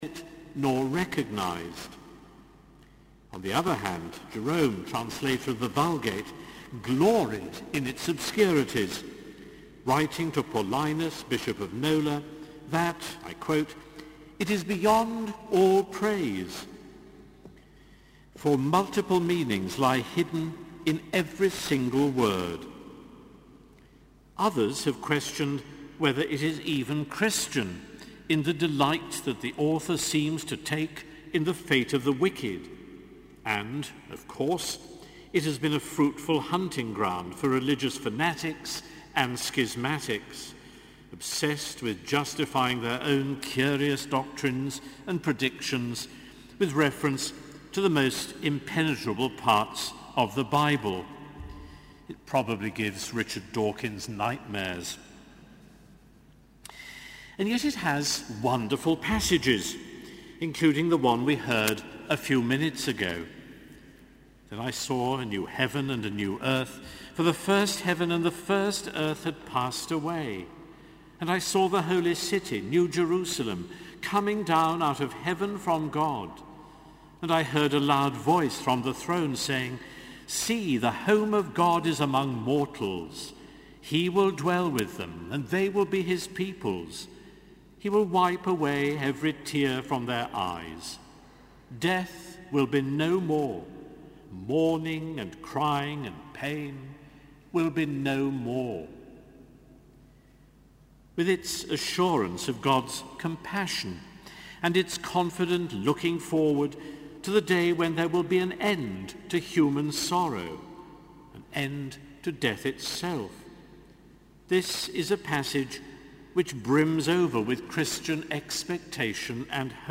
Sermon: Evensong - 18 May 2014